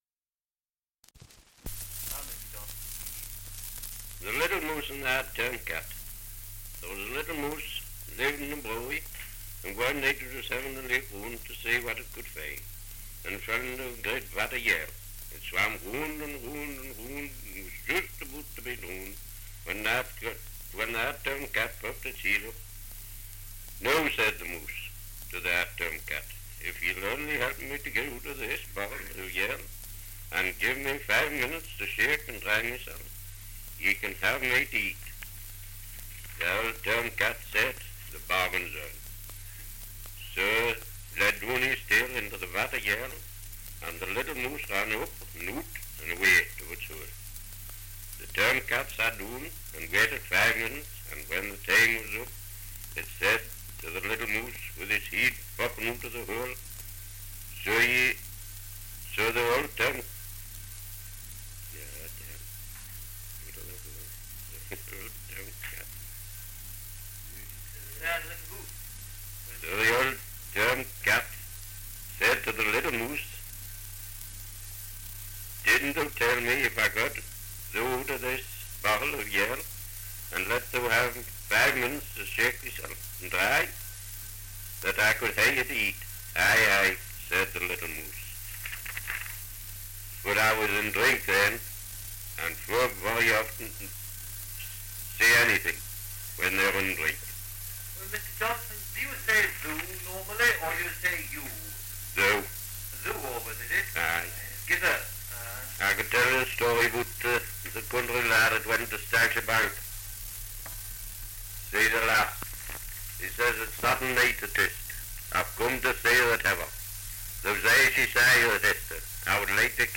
Dialect recording in Stamfordham, Northumberland
78 r.p.m., cellulose nitrate on aluminium